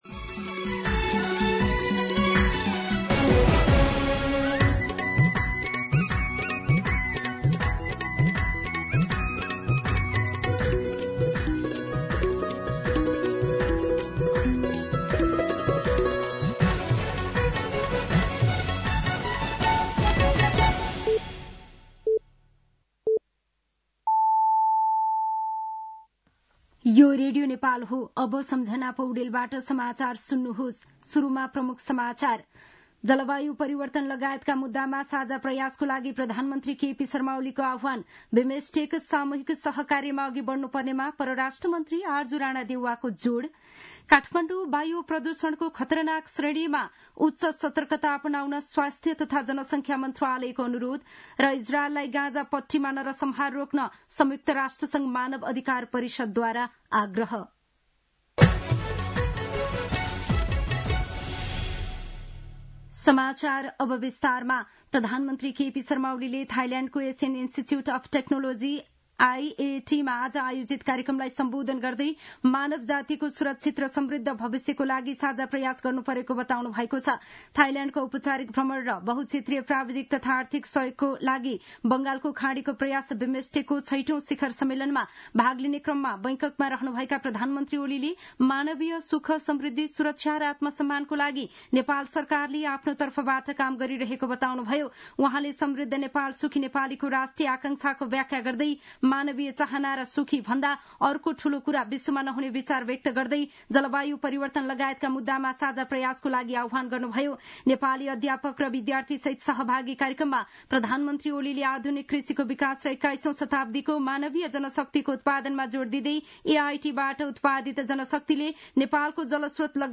दिउँसो ३ बजेको नेपाली समाचार : २१ चैत , २०८१
3-pm-Nepali-News.mp3